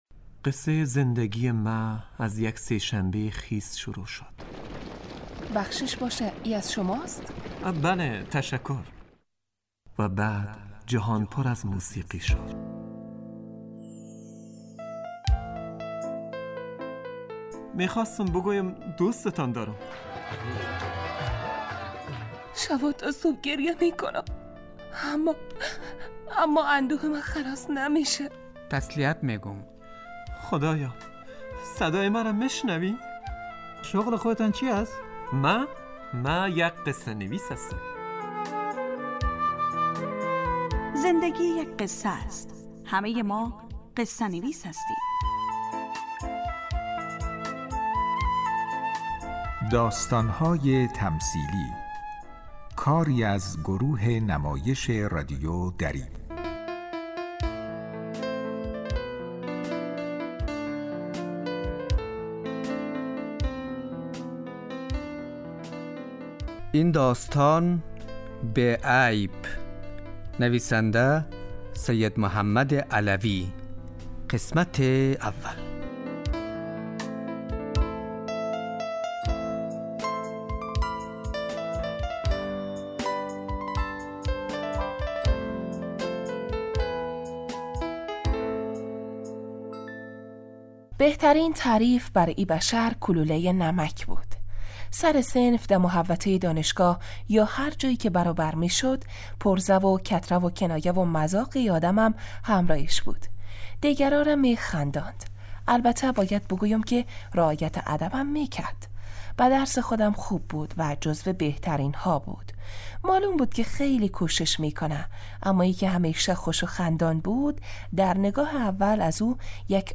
داستانهای تمثیلی نمایش 15 دقیقه ای هستند که روزهای دوشنبه تا پنج شنبه ساعت 03:25عصربه وقت افغانستان پخش می شود.